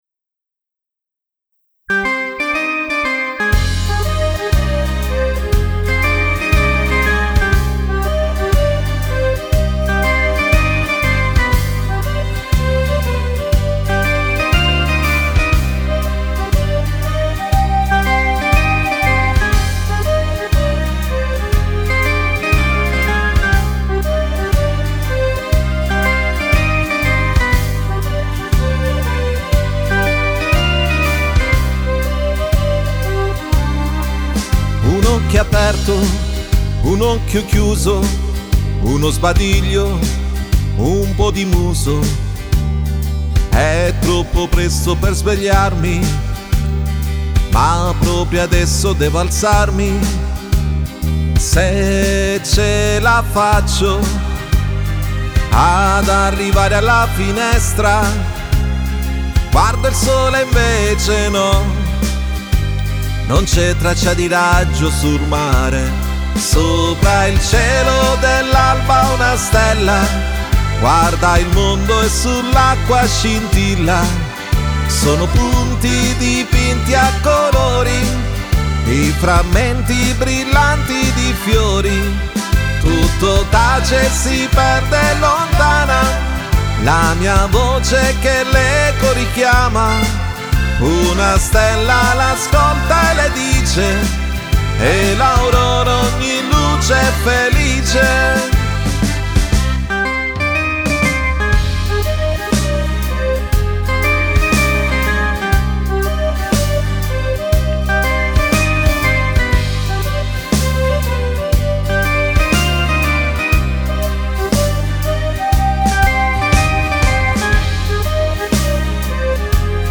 Moderato
Canzone-Moderato